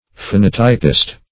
Meaning of phonotypist. phonotypist synonyms, pronunciation, spelling and more from Free Dictionary.
Phonotypist \Pho*not"y*pist\, n. One versed in phonotypy.